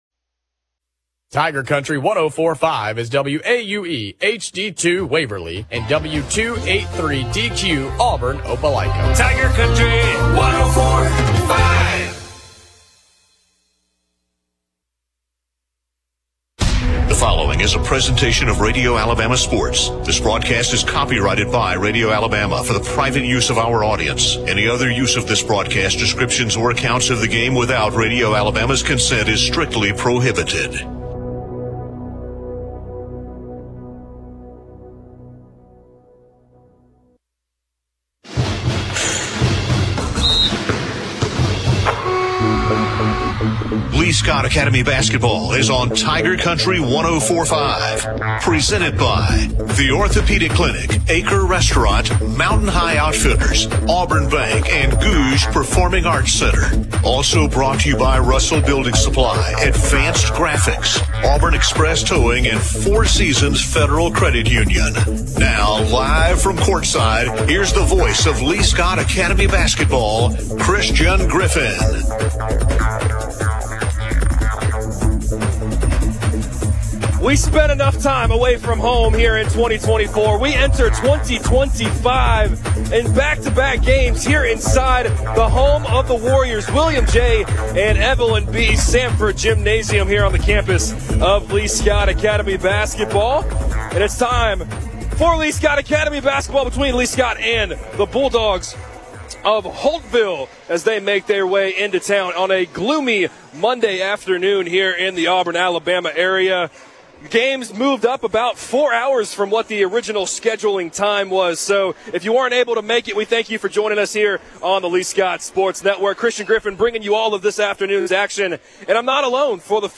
Jan. 6, 2025 - Listen as the Lee-Scott Warriors host the Holtville Bulldogs. The Warriors won 44-36.